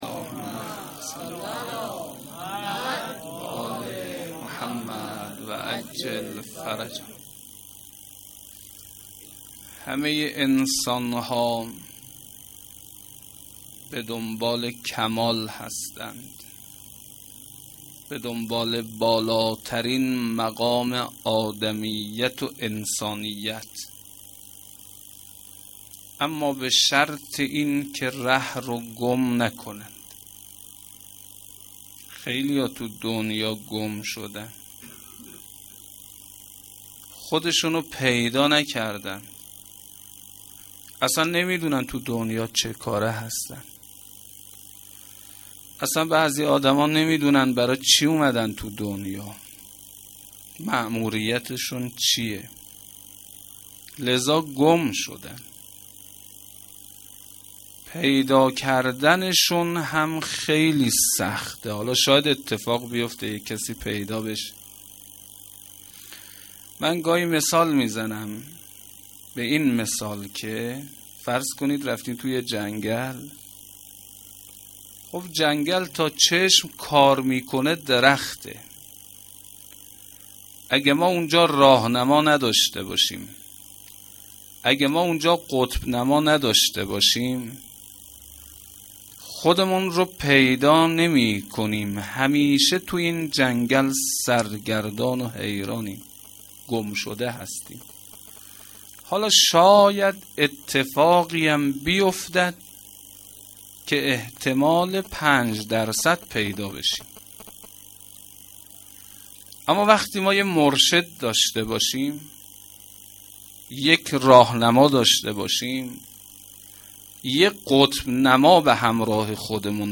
سخنرانی
sokhanrani-Rozatol-Abbas.shahadat-emam-Bagher.mp3